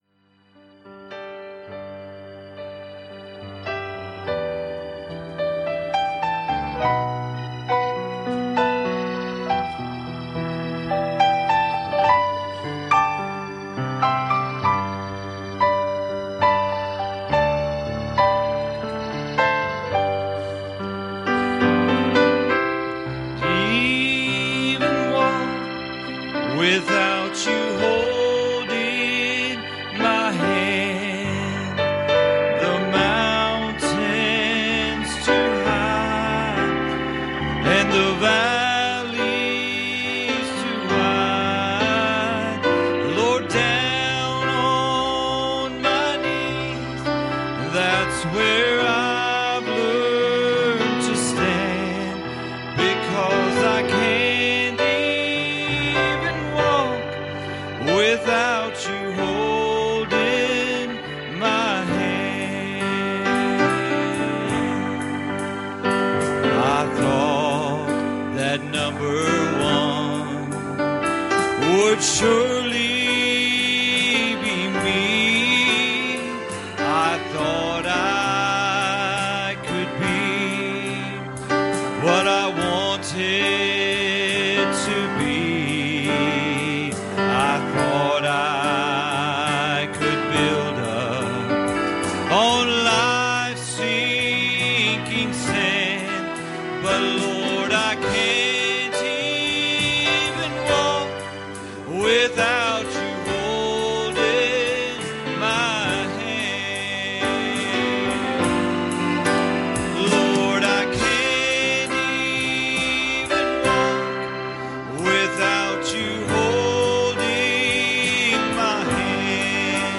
Passage: Revelation 2:12 Service Type: Sunday Evening